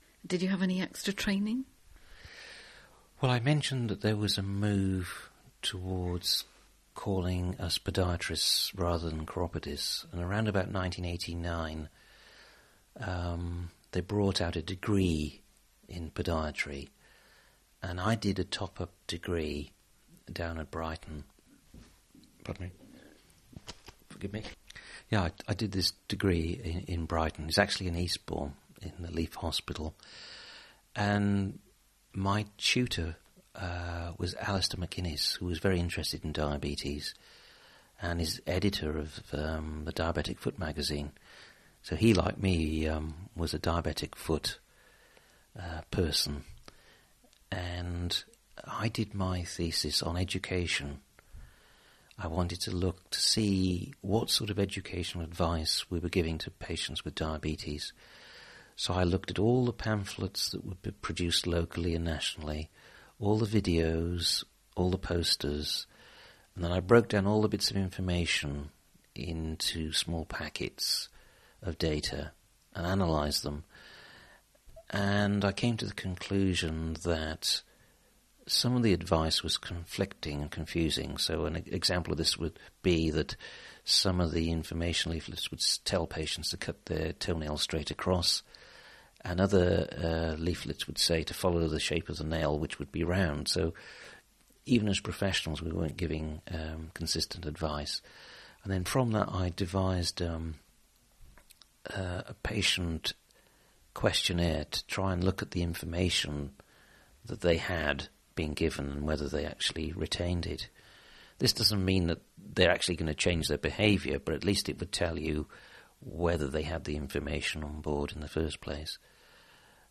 Available interview tracks